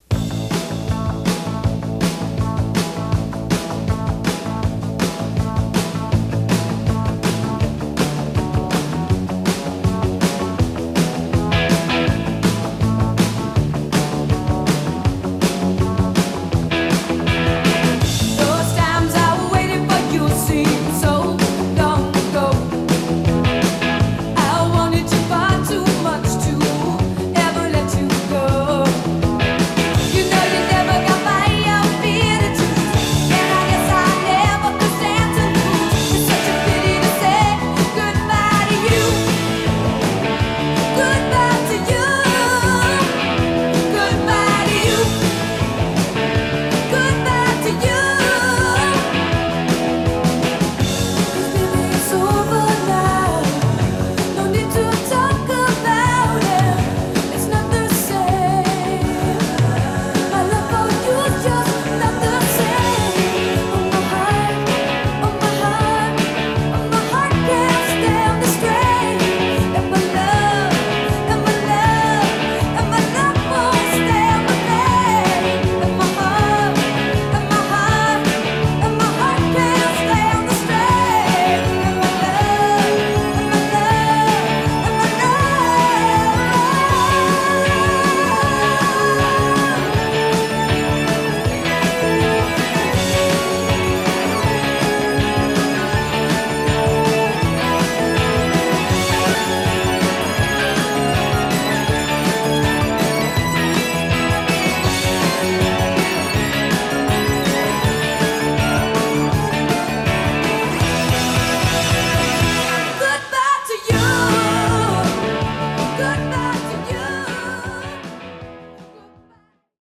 BPM156-163
Audio QualityMusic Cut